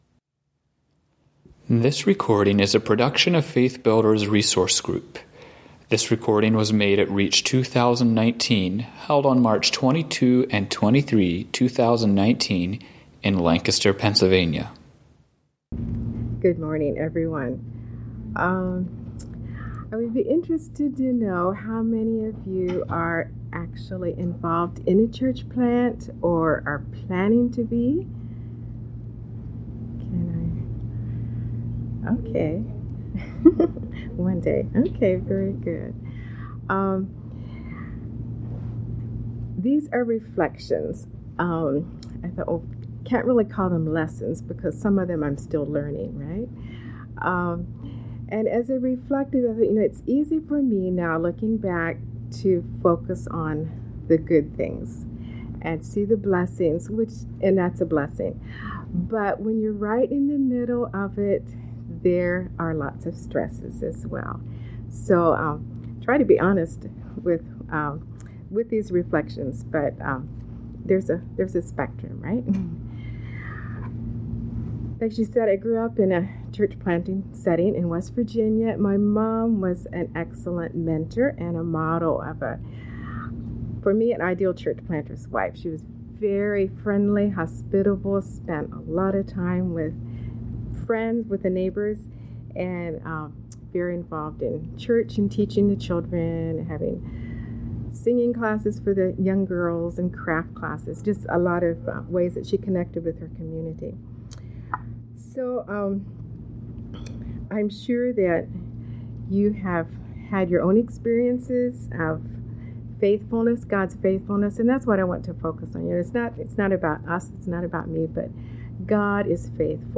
Home » Lectures » Reflections of Church Planter’s Wife